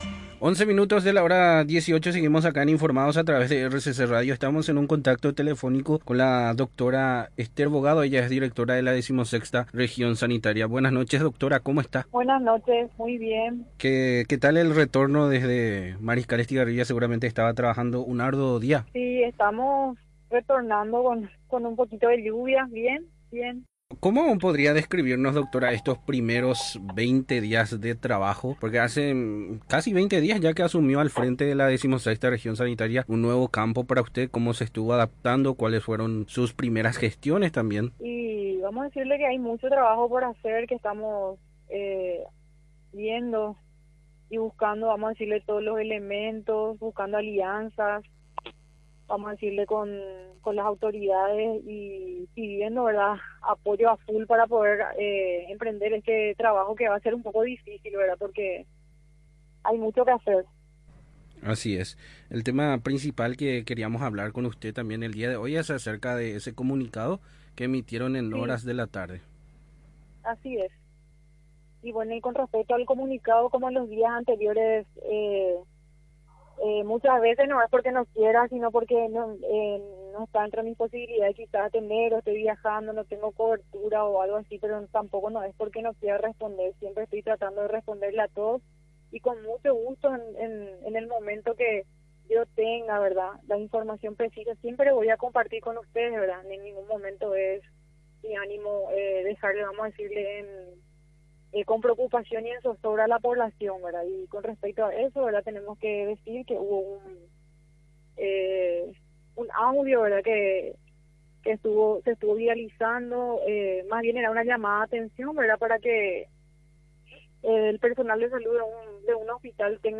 AUDIO DE LA ENTREVISTA COMPLETA: